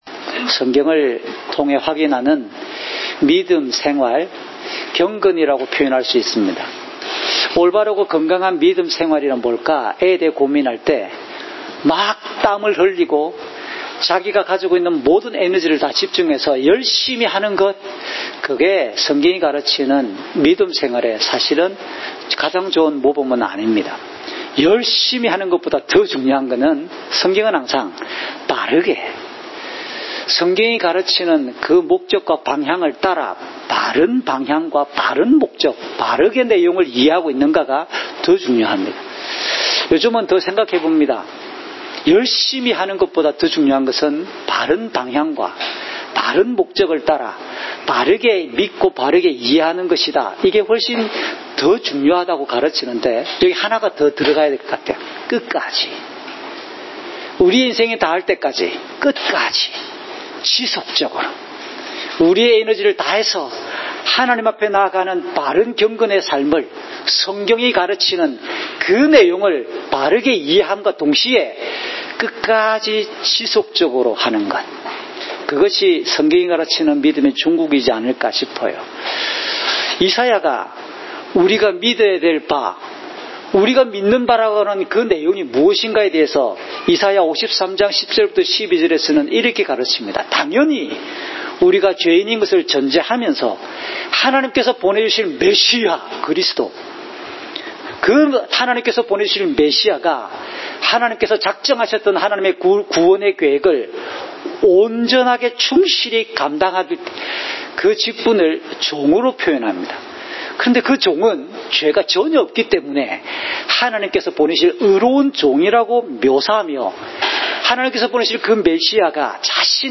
주일설교 - 2020년 6월 28일 "십자가는 속죄를 위한 참된 능력입니다!"(요12:1~19)